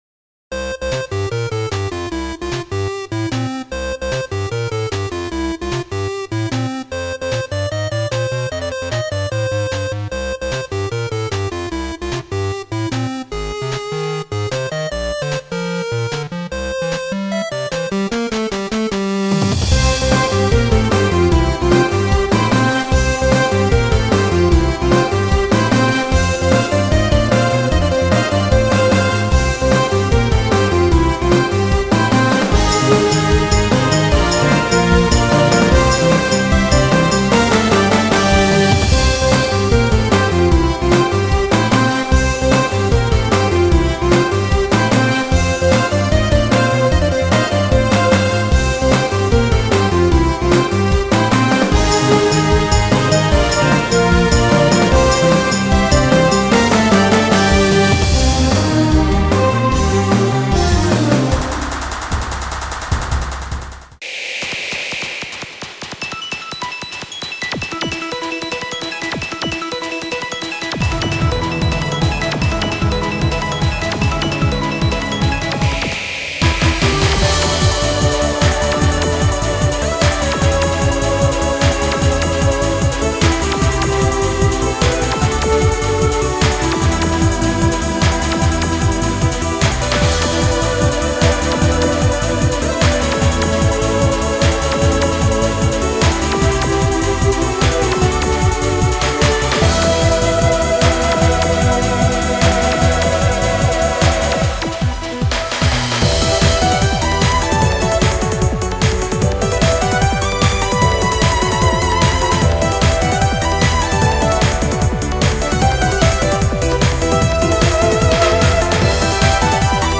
ゲーム音楽を原曲重視でアレンジしています。
クロスフェードデモ ～ ファミコン・レトロゲーム系